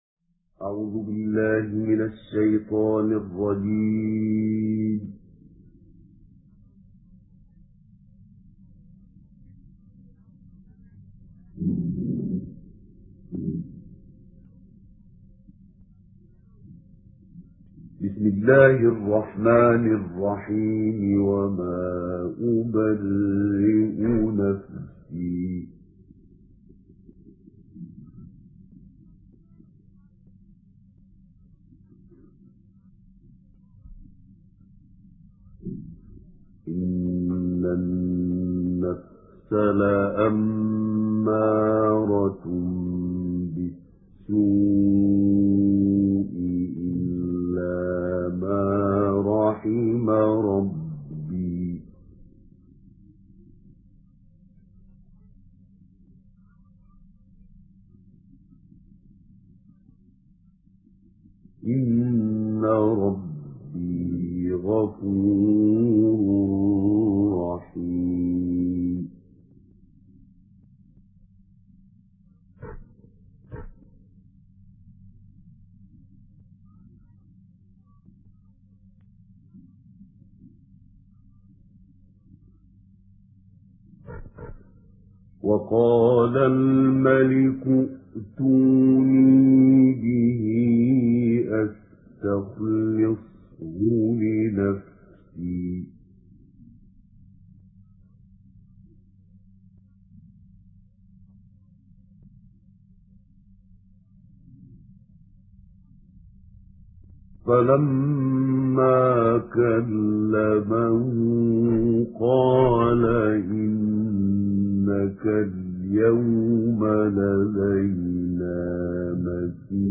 সুললিত কণ্ঠে কুরআন তিলাওয়াত